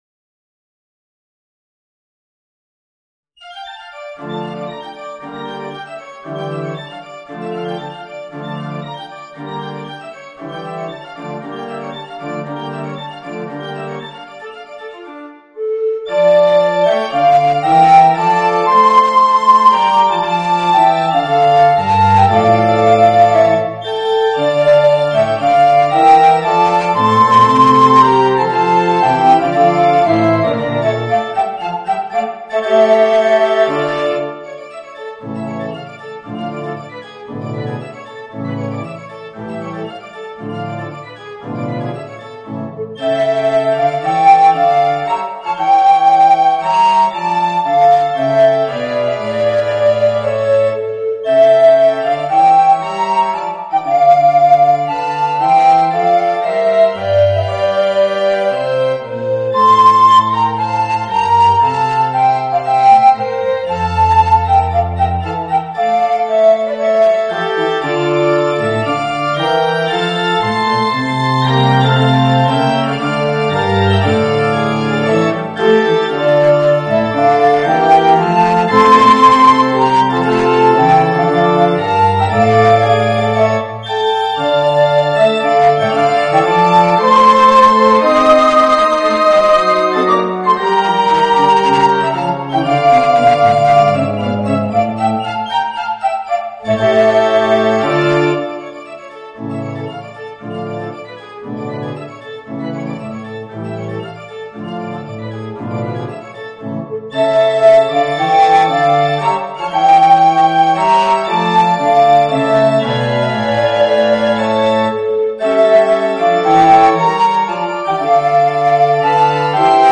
Voicing: Alto Recorder and Piano